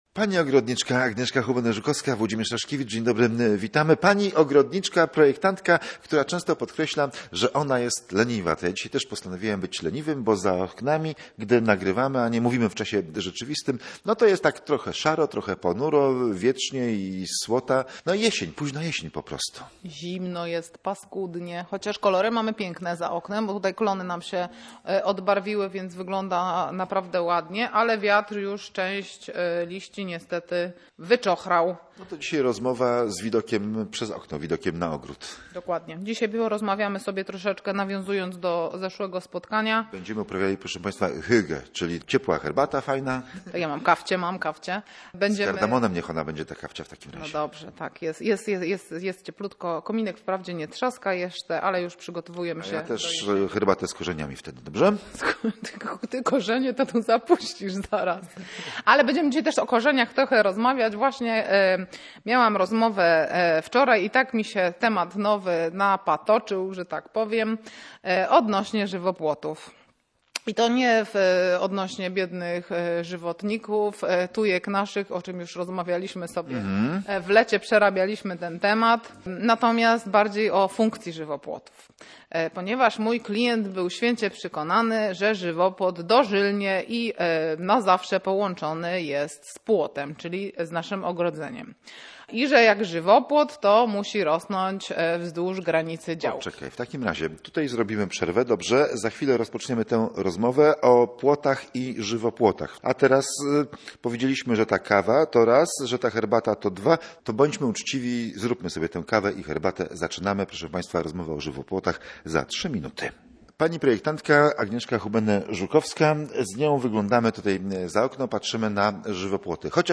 Ogród to nie spacerniak – czyli rozmowa o żywopłotach i tworzeniu ogrodowych wnętrz